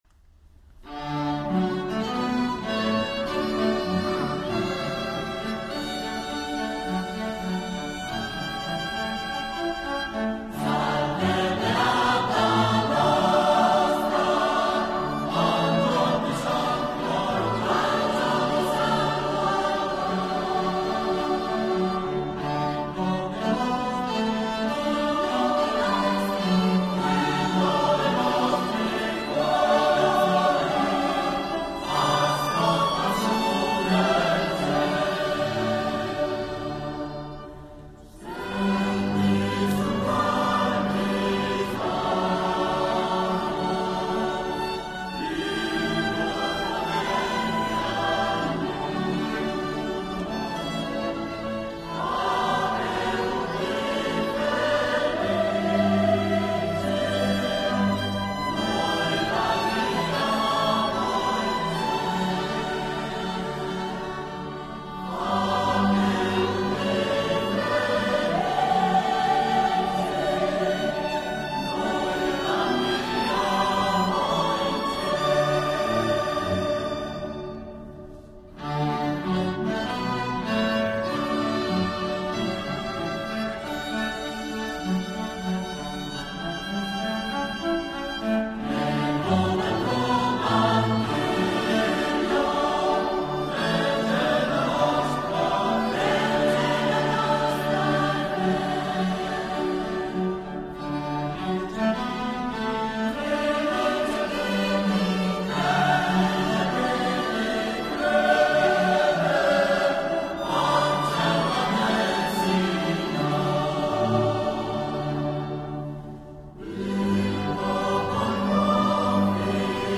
Salve Beata nostra Coro “S. Cecilia” di Calvisano e Gruppo d’Archi “Vergilius”